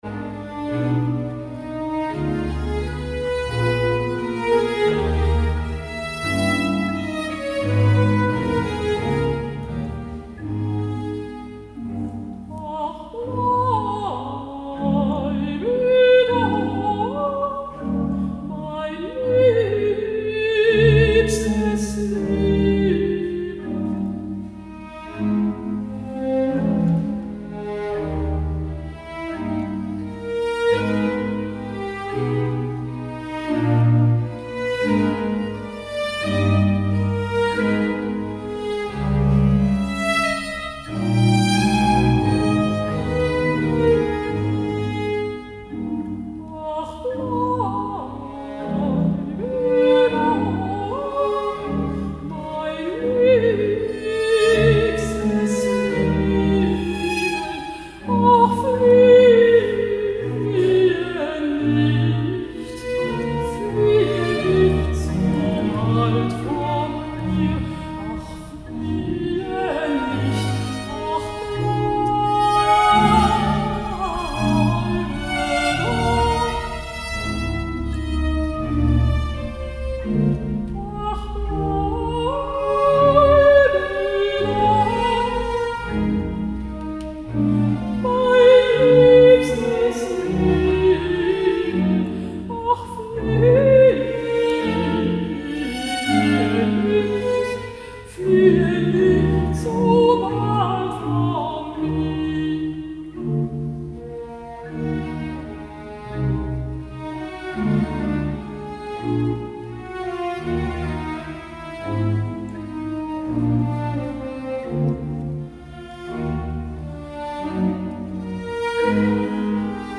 (Live-Mitschnitt)